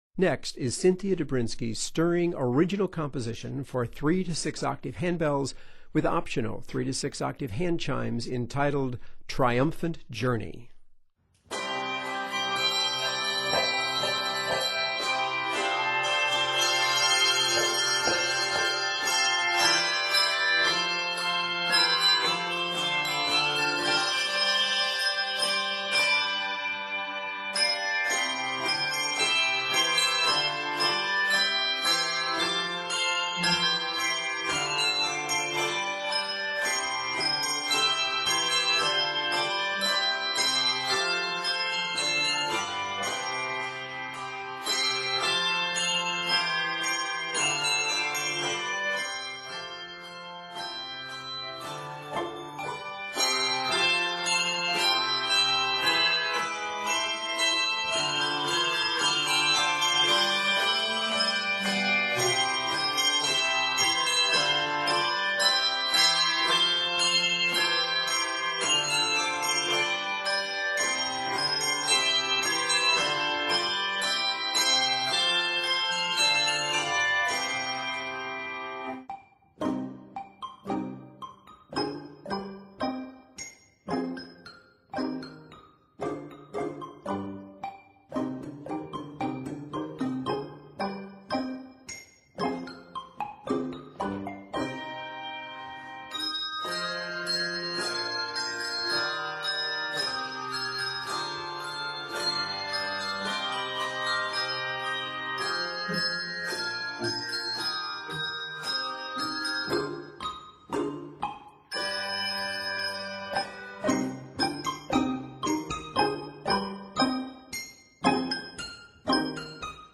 is scored in C Major and c minor and is 112 measures.